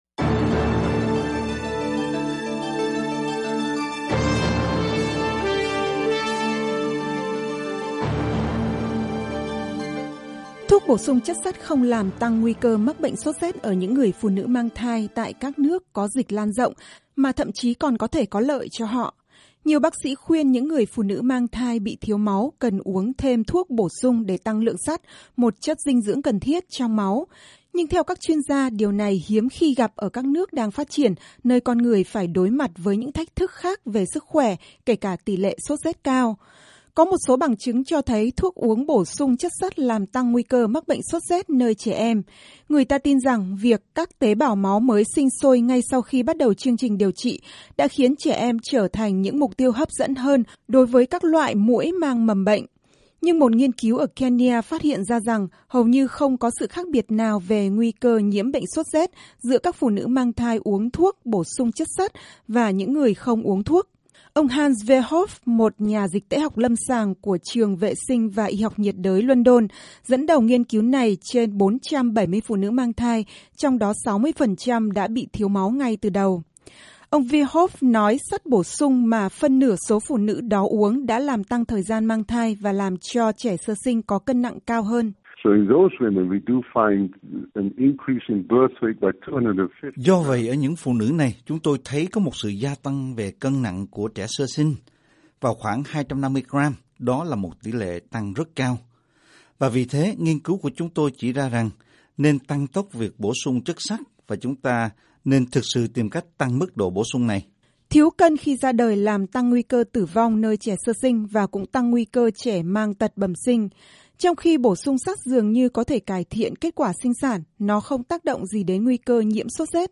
Bản tin khoa học hàng tuần: Chất sắt và vitamin E có ảnh hưởng thế nào tới thai sản